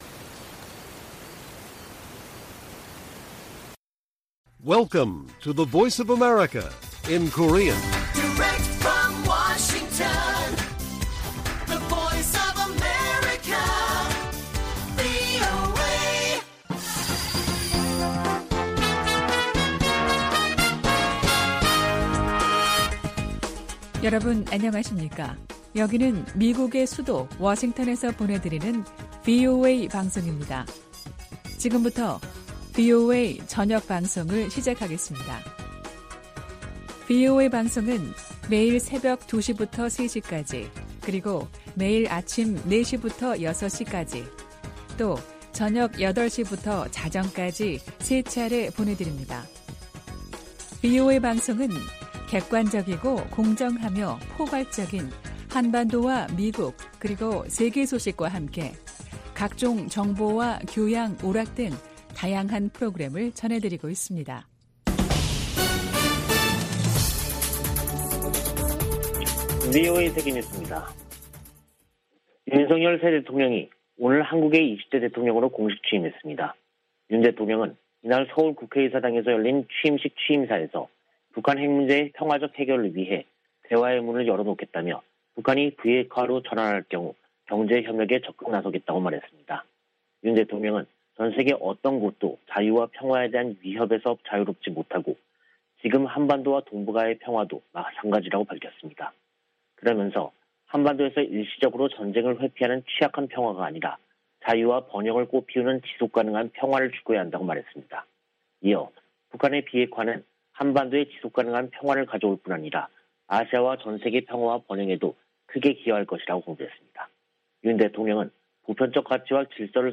VOA 한국어 간판 뉴스 프로그램 '뉴스 투데이', 2022년 5월 10일 1부 방송입니다. 윤석열 한국 대통령이 10일 취임했습니다. 윤 대통령은 비핵화로 전환하면 북한 경제를 획기적으로 개선할 계획을 준비하겠다고 밝혔습니다.